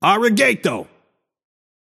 Shopkeeper voice line - Ari-gay-to.